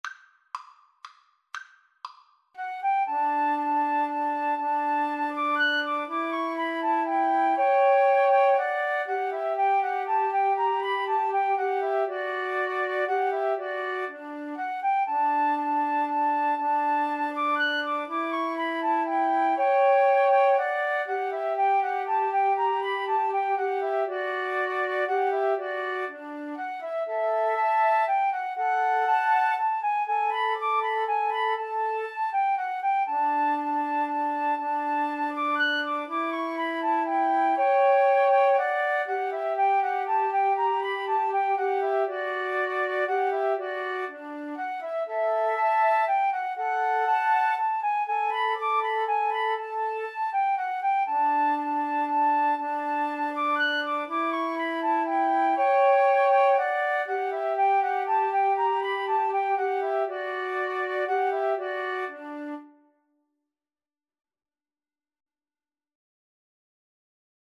3/4 (View more 3/4 Music)
Classical (View more Classical Flute Trio Music)